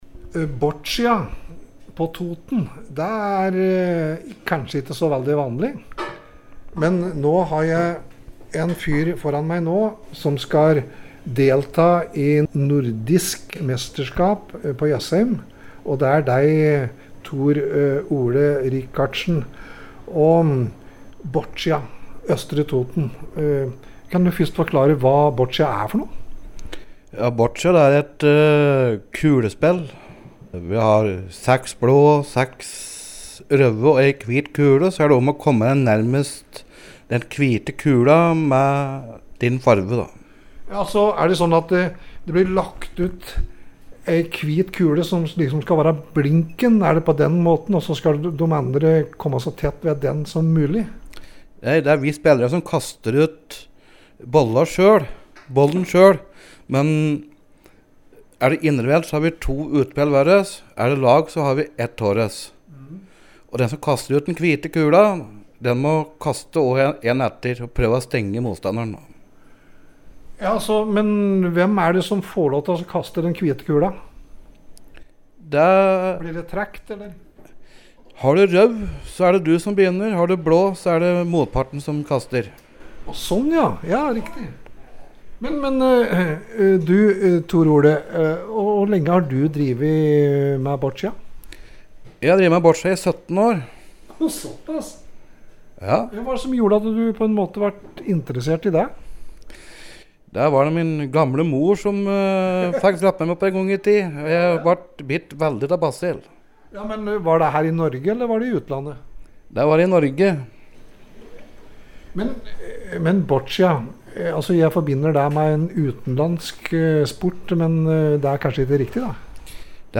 Innslag
Her forteller han om sporten til vår reporter: